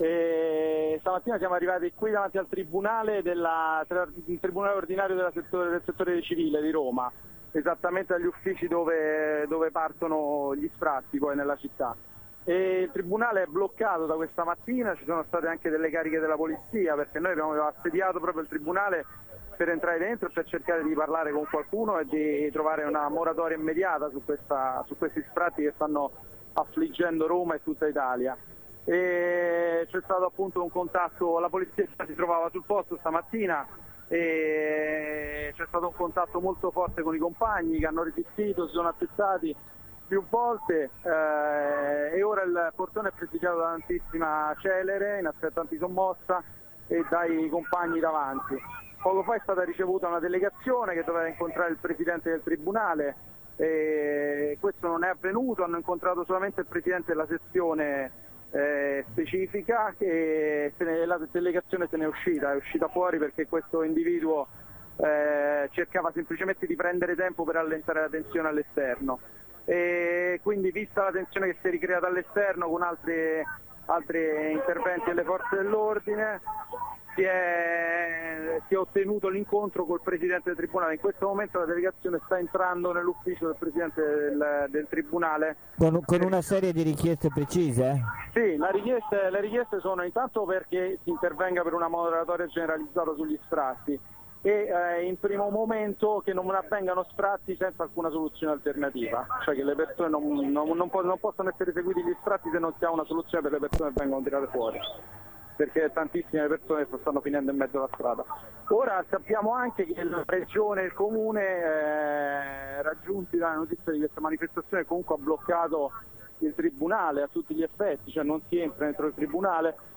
Intanto da Roma un corteo per il diritto all’abitare aveva raggiunto e assediato il tribunale, costringendo la celere ad alcune cariche di alleggerimento, abbiamo sentito un compagno dei Blocchi Precari Metropolitani